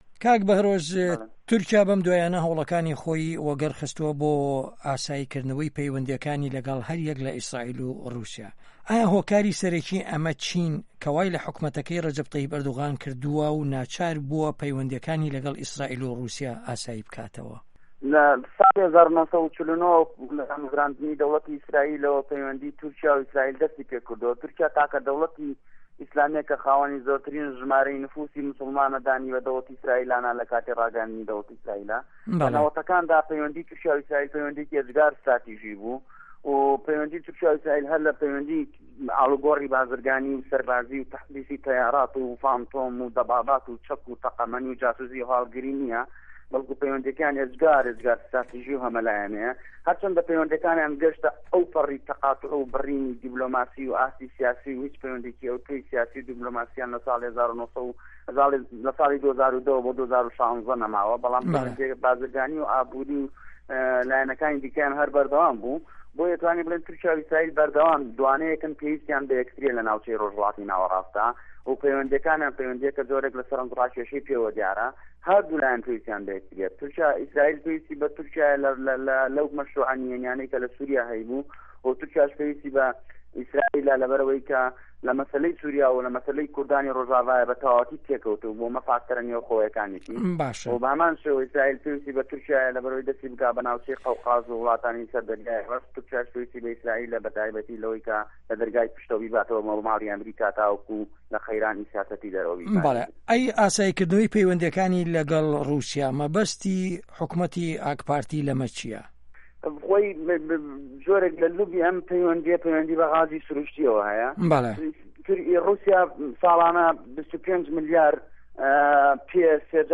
تورکیا - گفتوگۆکان